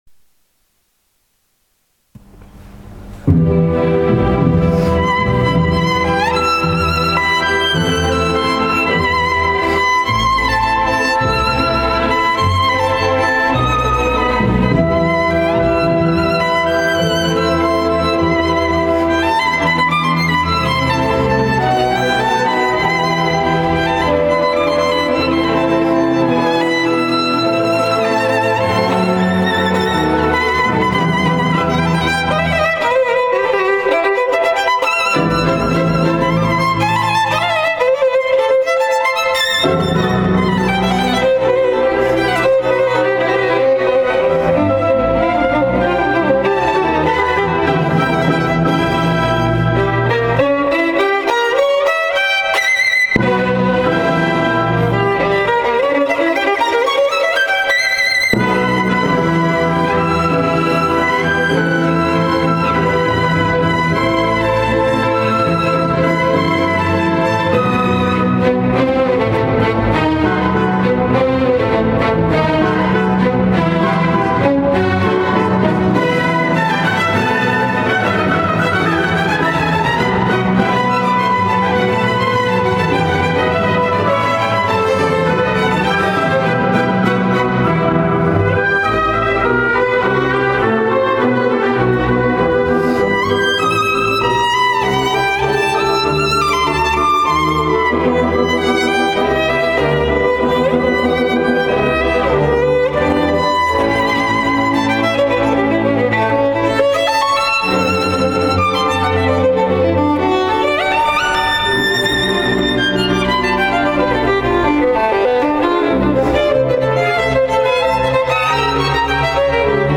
89 「 ﾊﾞｲｵﾘﾝ協奏曲 」
Violin